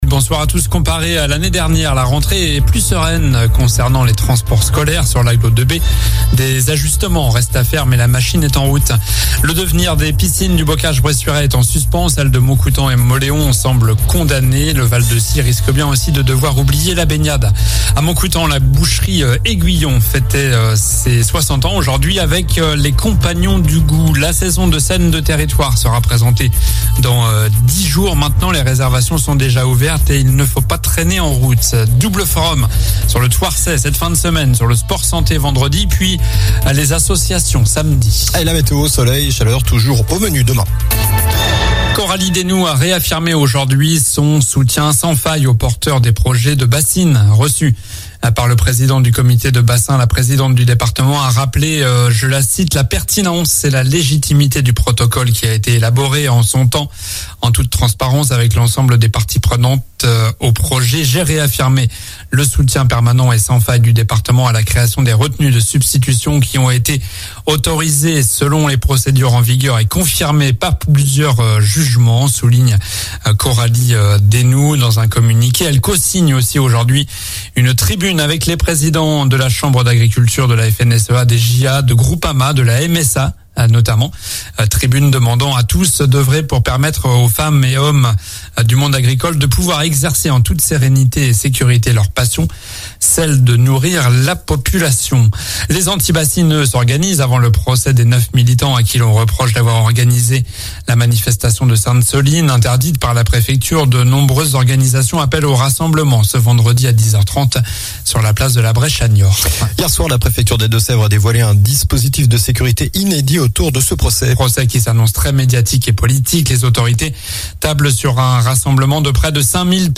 Journal du mercredi 6 septembre (soir)